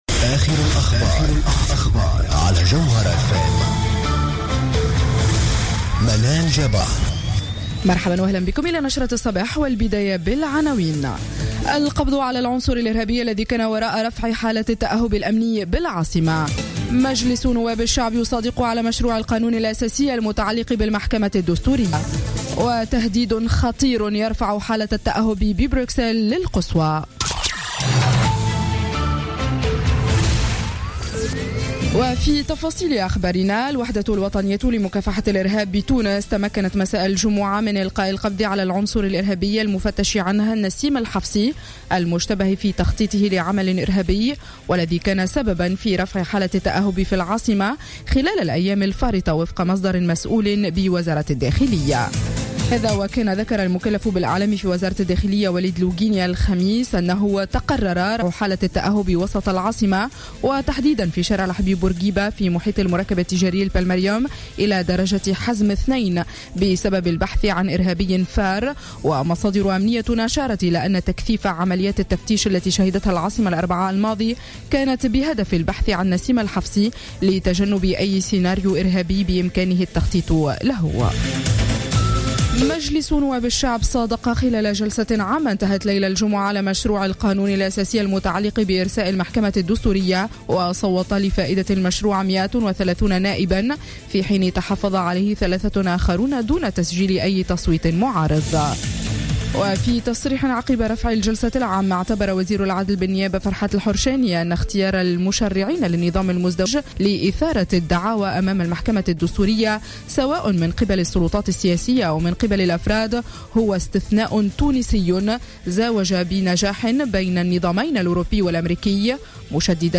نشرة أخبار السابعة صباحا ليوم السبت 21 نوفمبر 2015